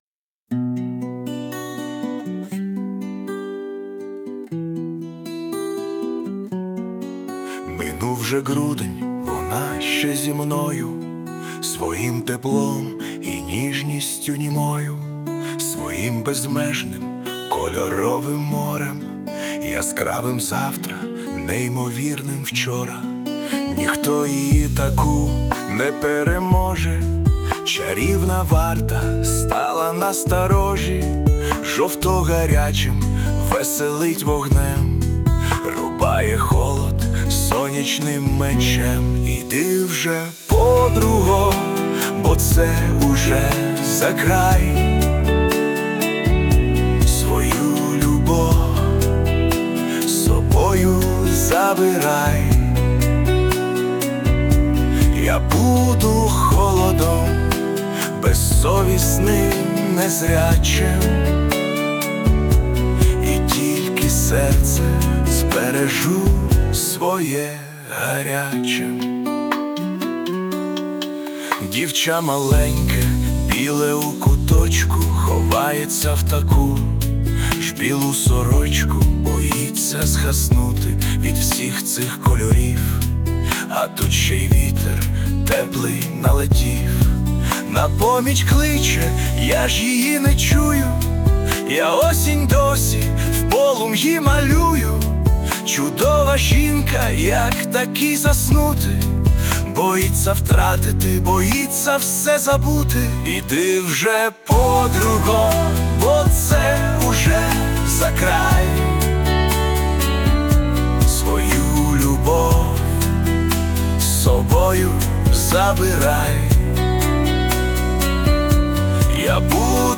СТИЛЬОВІ ЖАНРИ: Ліричний
Дуже гарно! 16 Тепла пісня попри все. give_rose
12 Приємно слухати Ваші мелодійні пісні. friends hi